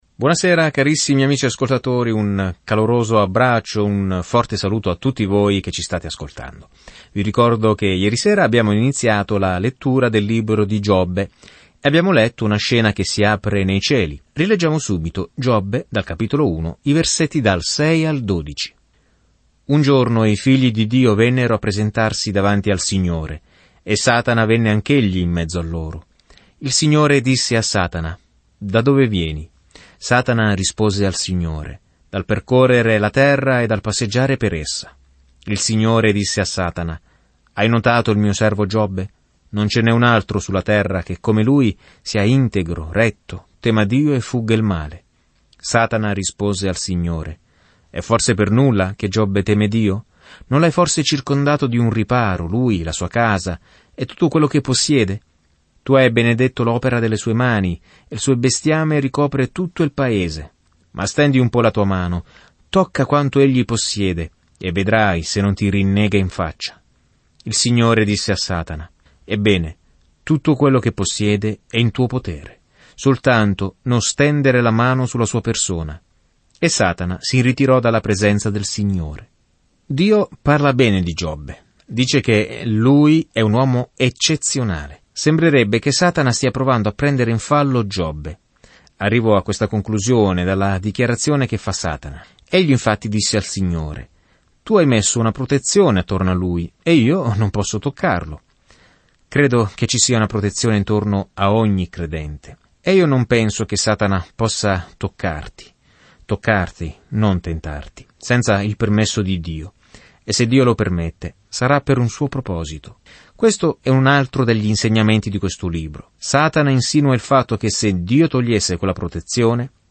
Scrittura Giobbe 2 Giobbe 3:1-10 Giorno 1 Inizia questo Piano Giorno 3 Riguardo questo Piano In questo dramma tra cielo e terra, incontriamo Giobbe, un uomo buono, che Dio ha permesso a Satana di attaccare; tutti hanno così tante domande sul perché accadono cose brutte. Viaggia ogni giorno attraverso Giobbe mentre ascolti lo studio audio e leggi versetti selezionati della parola di Dio.